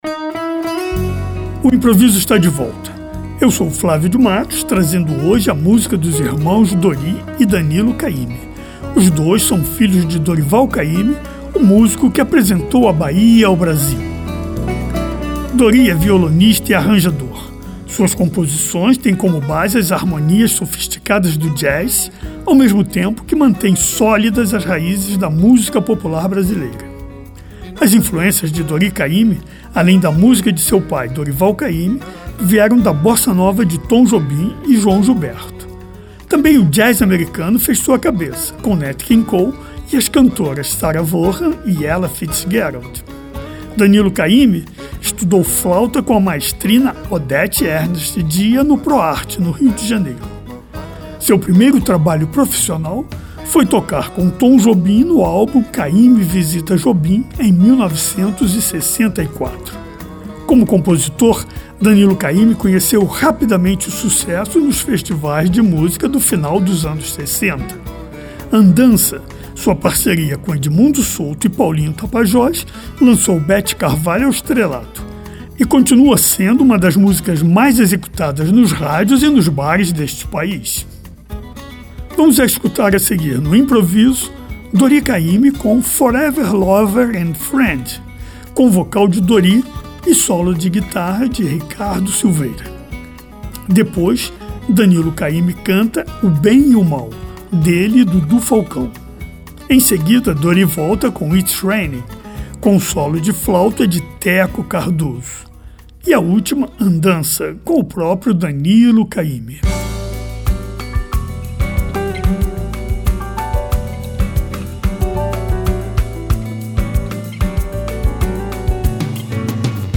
Tornou-se compositor, arranjador e um exímio violonista.